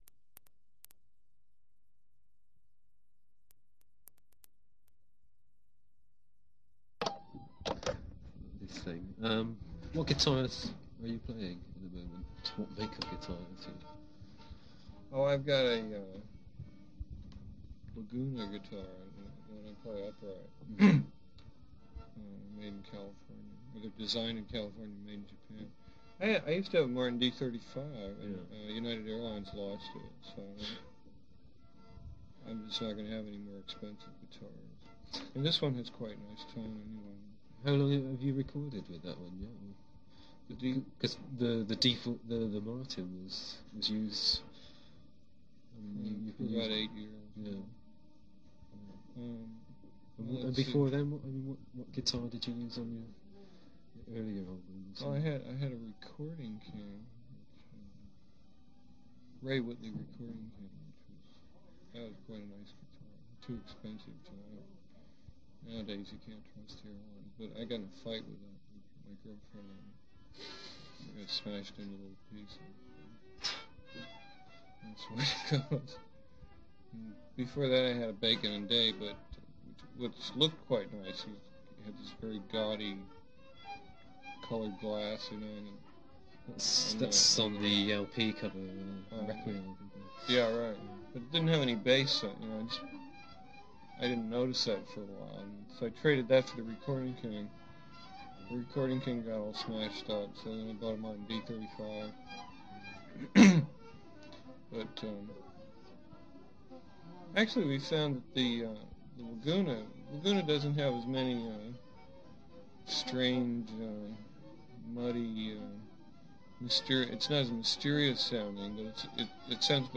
This was at the Cambridge Folk Festival, UK in 1983.